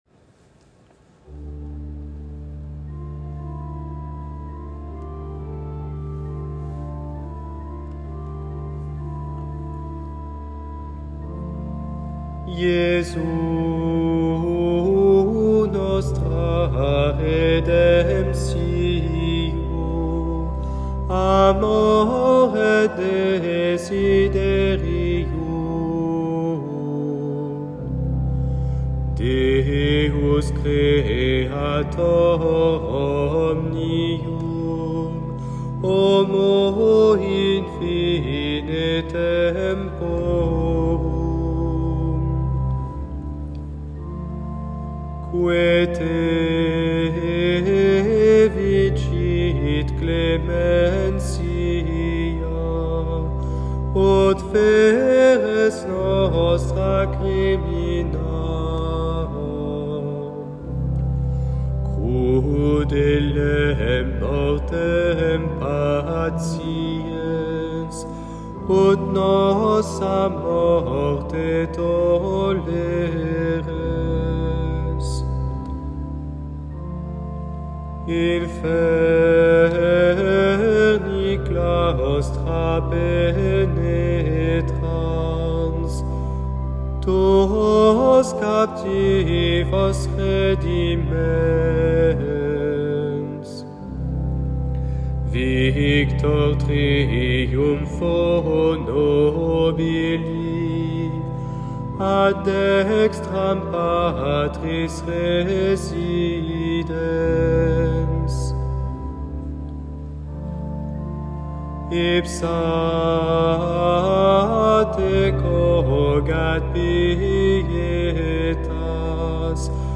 vepres-temps-pascal-le-jour-de-lascension-latin.m4a